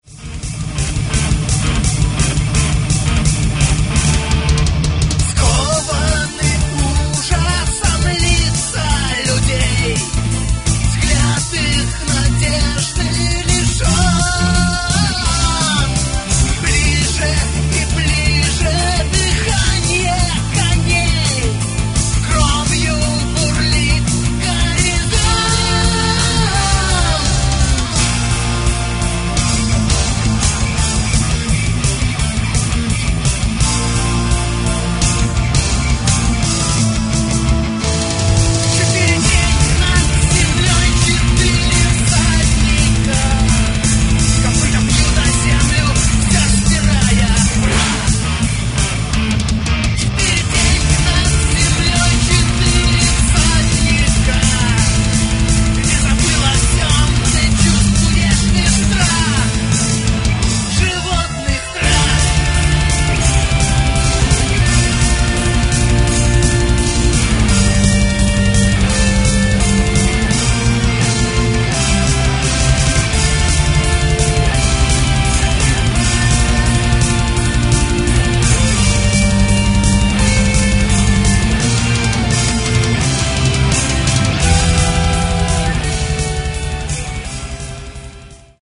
Гитары, бас, клавиши, перкуссия, вокал
фрагмент (519 k) - mono, 48 kbps, 44 kHz